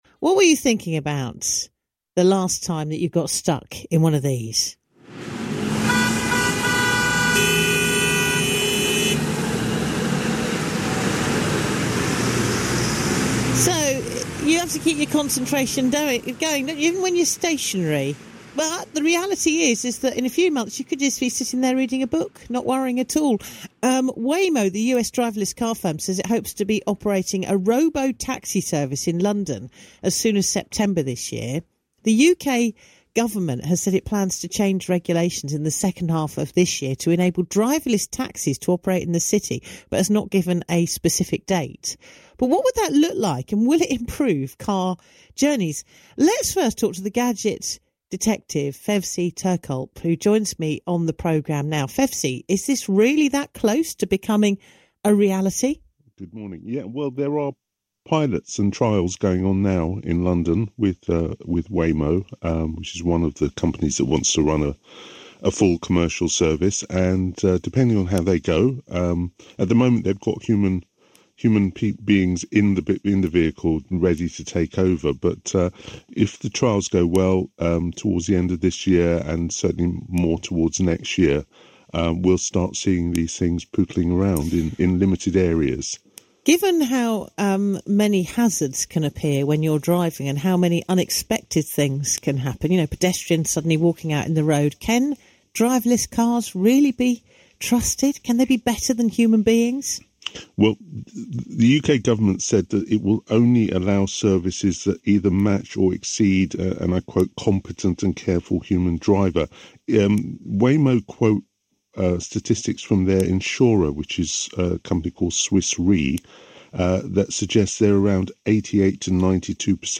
Gadget Detective - A selection of free tech advice & tech news broadcasts
17th February 2026 - Discussing Driverless Taxis on BBC Radio Surrey